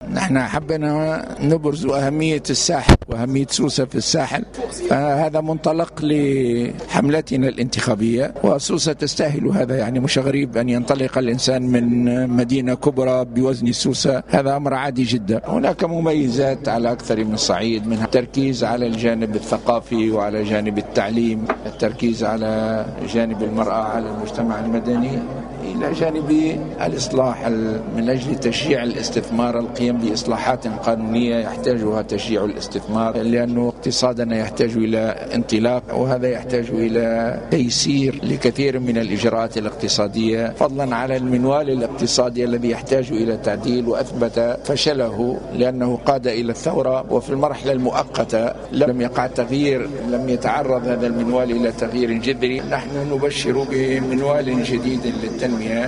وأكد الغنوشي في تصريح لجوهرة أف أم أنه من الطبيعي ان تنطلق الحملة الانتخابية لحركة النهضة من مدينة كبيرة بحجم سوسة . كما تحدث على البرنامج الانتخابي لحزبه و الذي سيرتكز على عدة جوانب أهمها التعليم والمرأة والإصلاح الاقتصادي لدفع نسق الاستثمار.